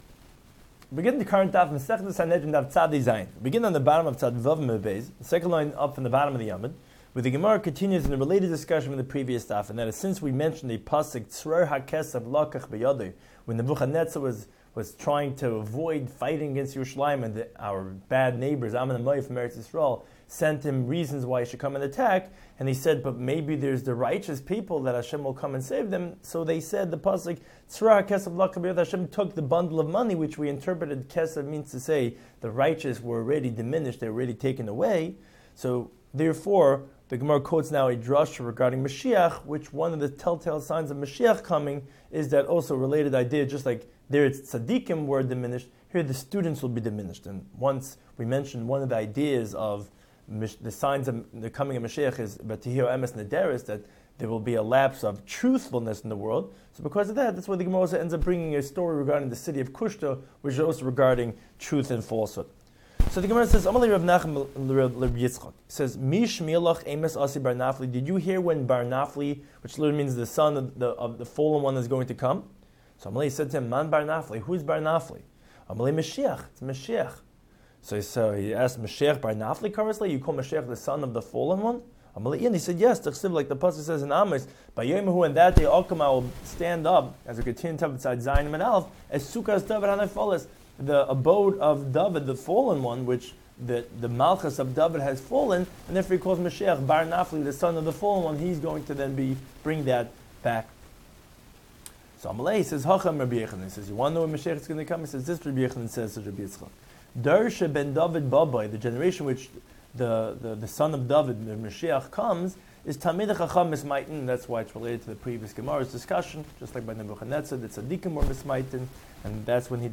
Daf Hachaim Shiur for Sanhedrin 97